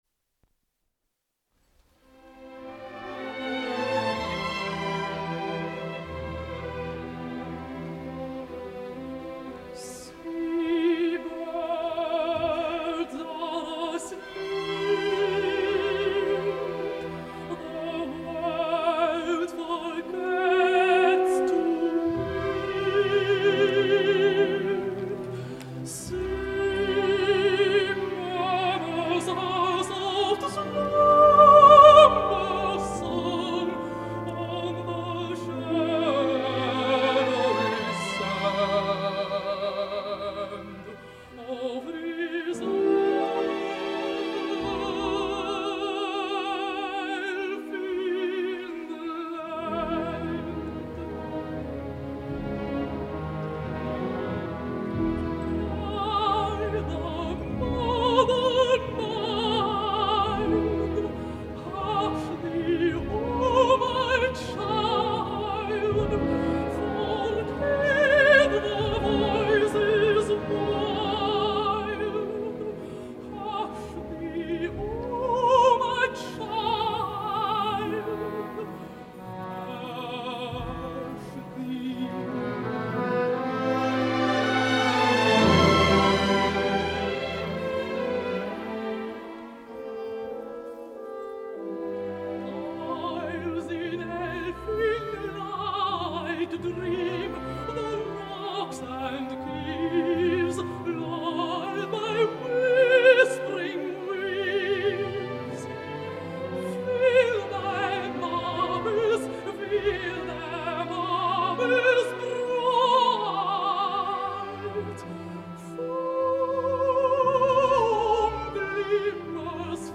El passat 16 de desembre moria a Huizen (Holanda) la contralt Aafje Heynis.
Arran de la seva mort m’ha arribat la gravació radiofònica que avui porto a IFL.